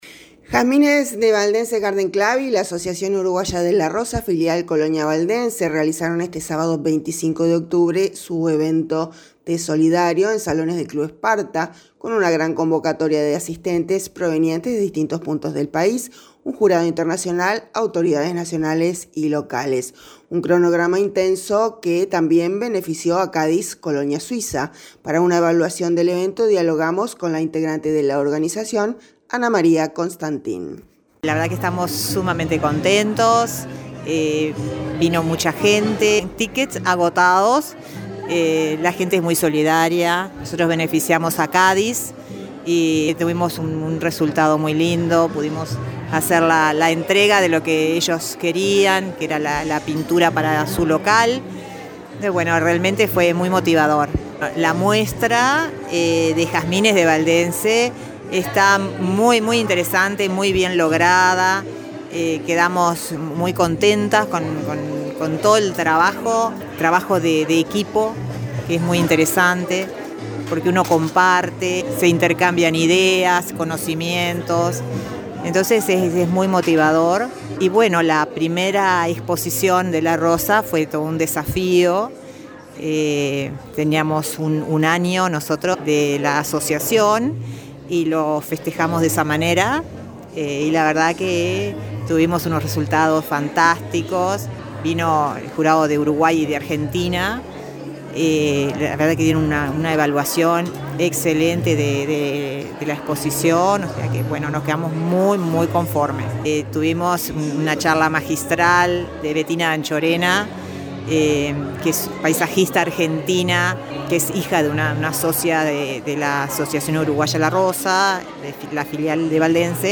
dialogamos